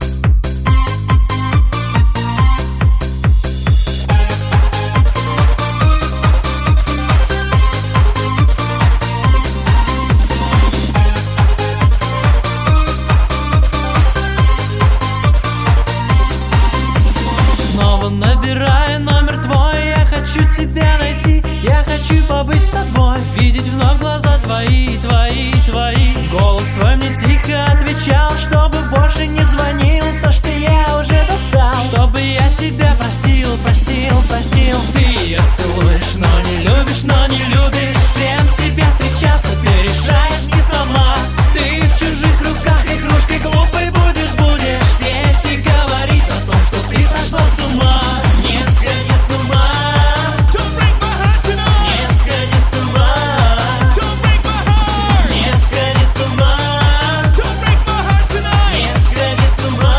Pop
100 лучших танцевально-лирических хитов.